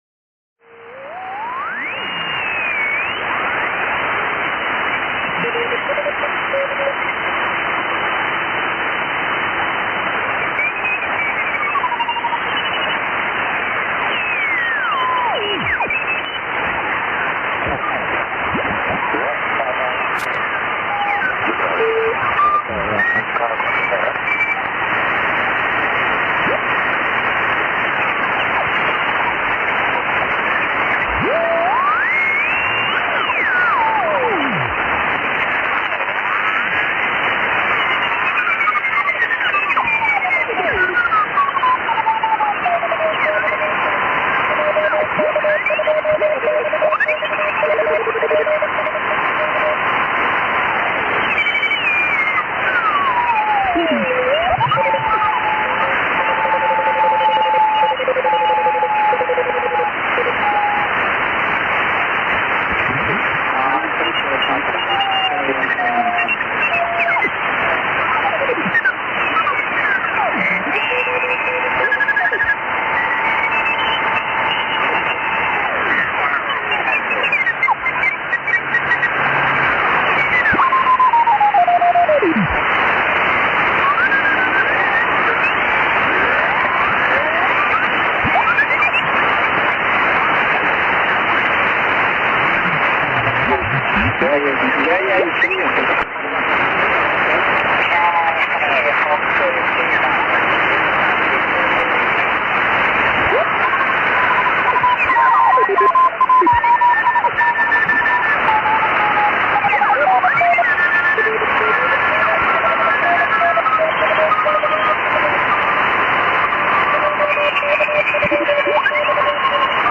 Mode-J ですので、当局は435Mhz 6エレ八木での受信。写真のように東側通過の軌道でしたので、建物の影が多い、当局にとって苦手のコース。録音は AOS から４分間ぐらいのものです。
事前の公開アナウンスもあったことと、日曜日だったのでそれなりのにぎわい。返り信号の確認が多いのは「初物」の小手試しか。当局も交信ターゲットが定まらず、アップリンクもイマイチ。